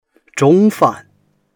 zhong1fan4.mp3